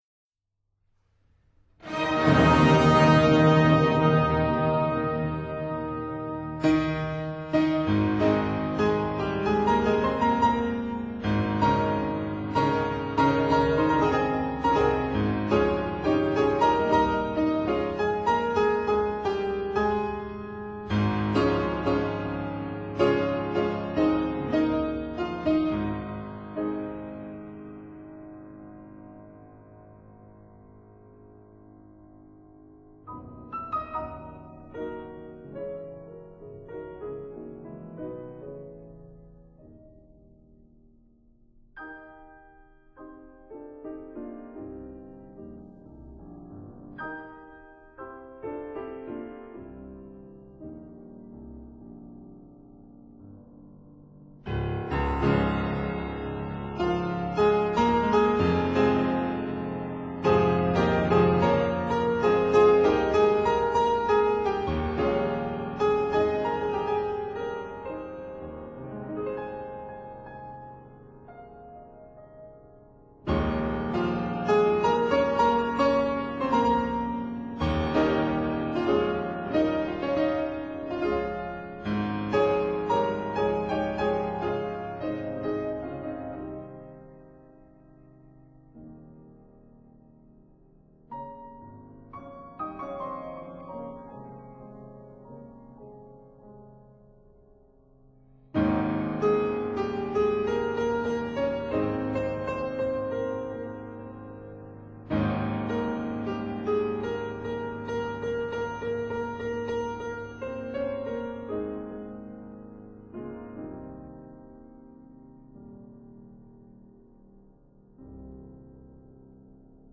for piano & orchestra